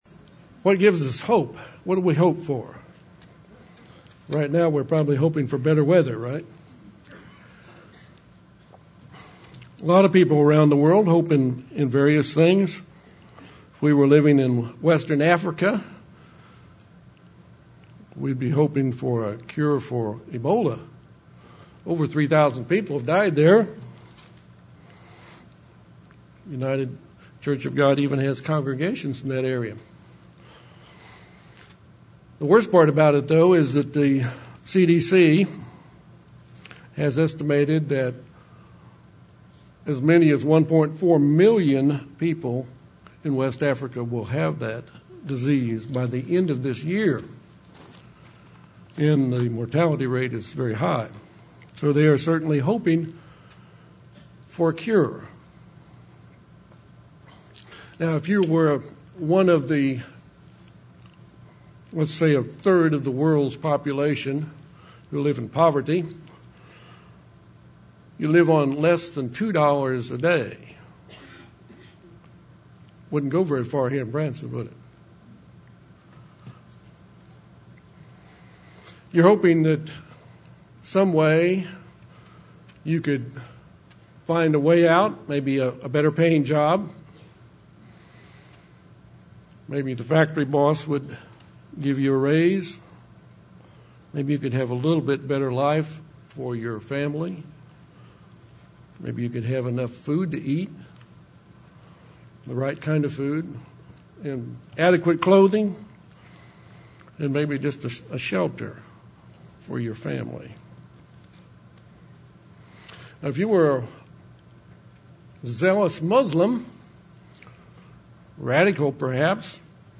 This sermon was given at the Branson, Missouri 2014 Feast site.